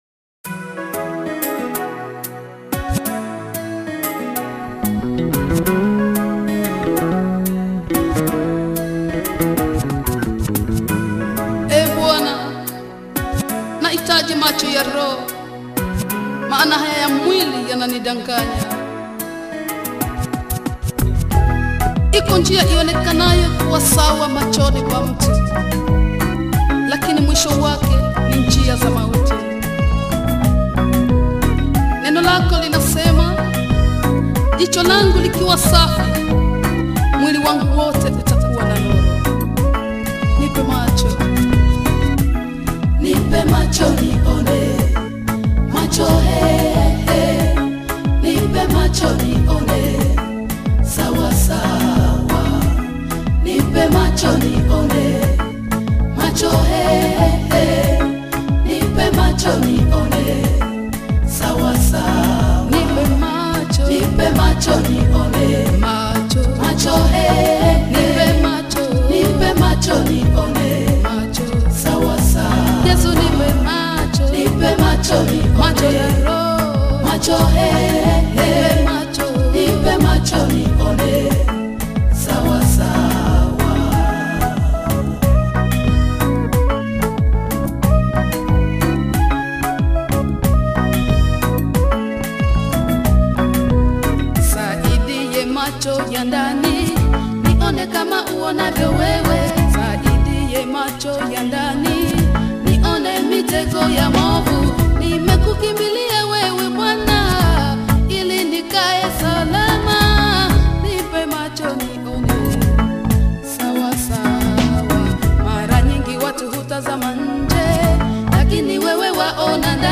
Both praise and worship songs.